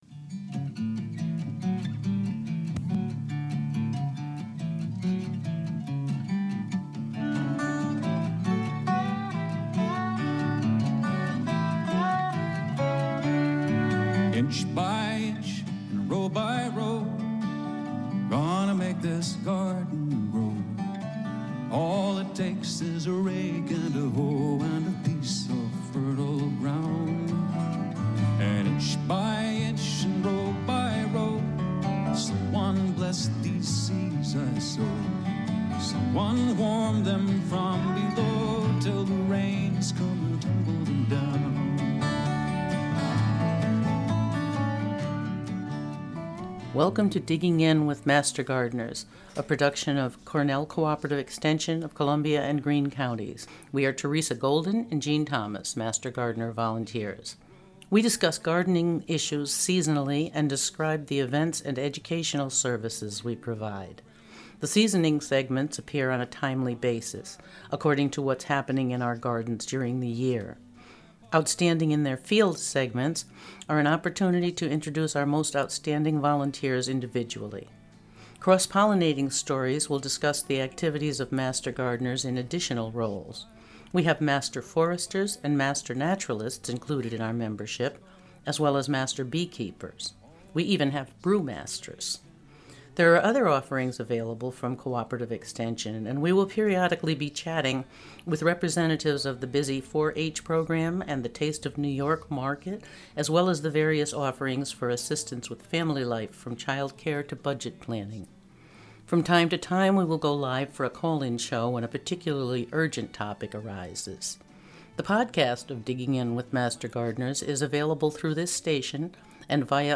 A conversation about gardening issues seasonally, including interviews with Master Gardener Volunteers and experts in many fields related to gardening.